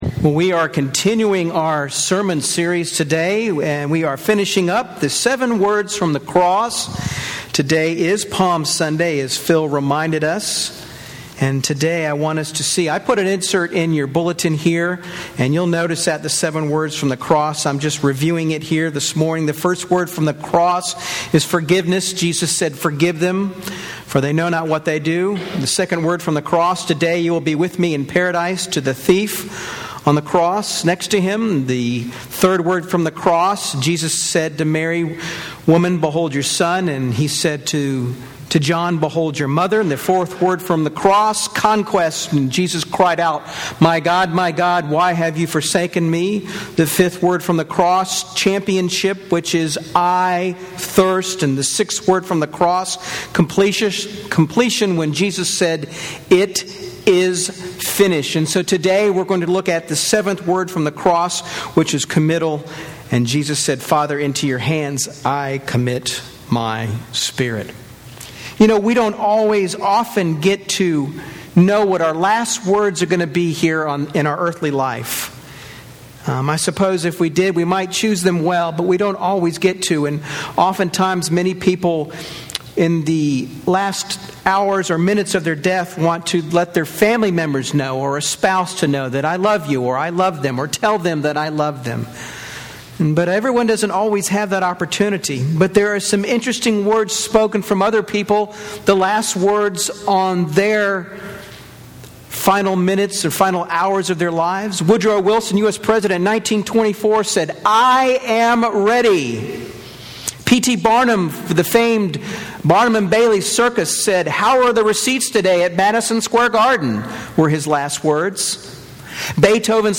In this message we conclude our sermon series: 7 Words from the Cross. In it we are faced with the decision to define our relationship with Jesus Christ.